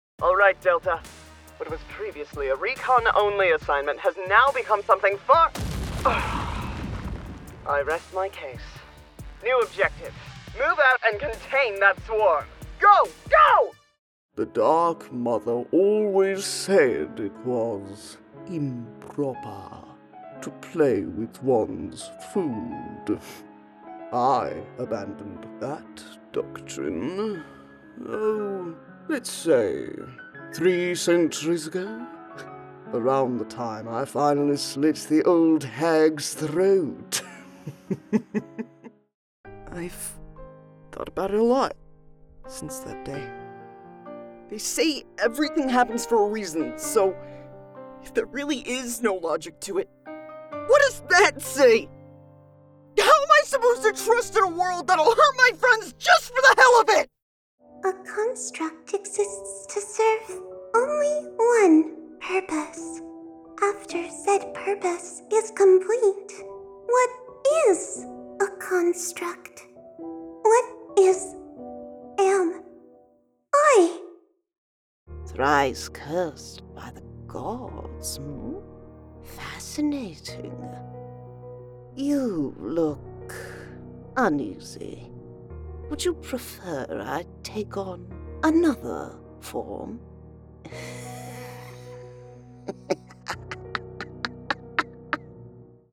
Videogame Demo (Updated)